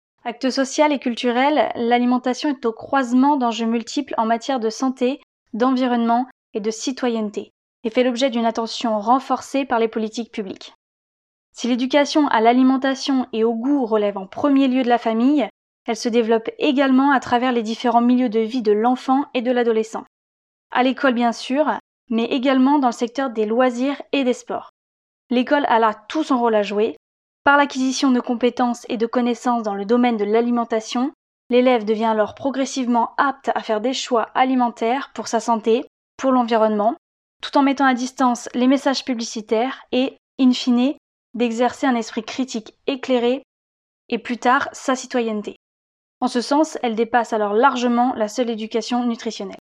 Voix Off Reportage
20 - 40 ans